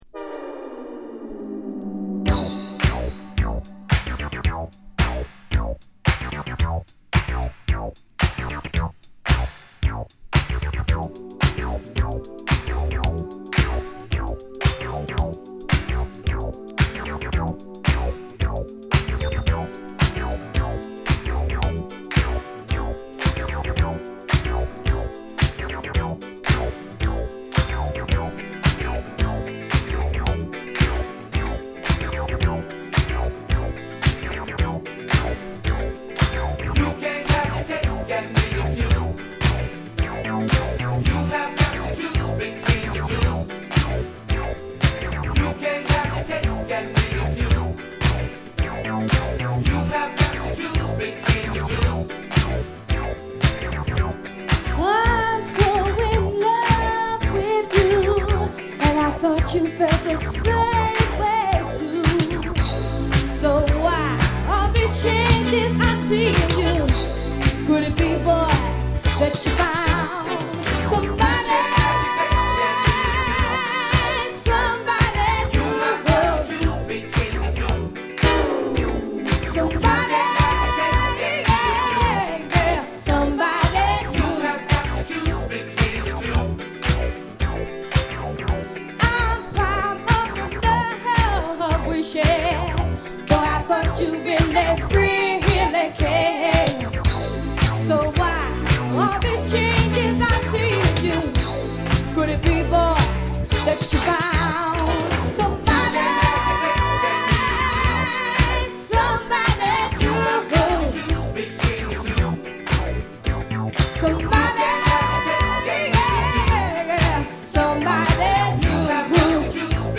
コーラスフレーズがクセになる都会的なエレクトロ・ブギーチューン！